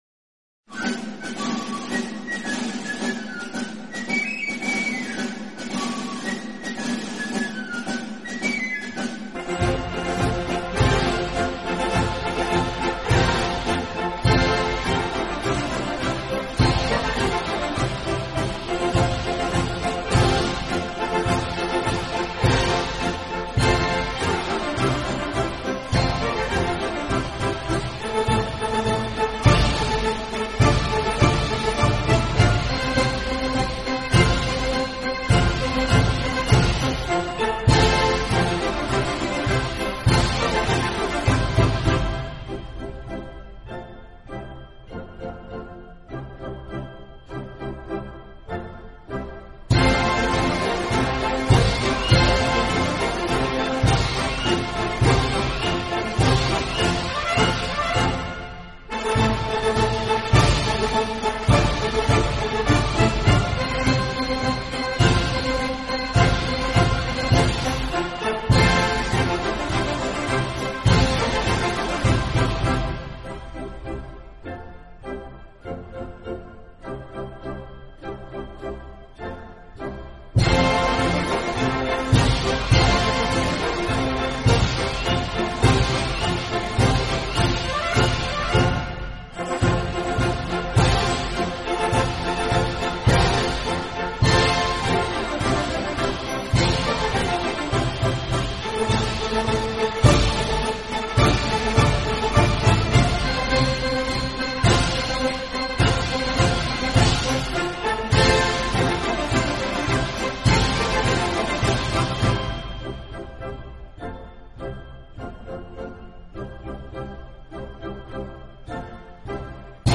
독일의 행진곡 '요크셔 행진곡' Yorcksher sound effects free download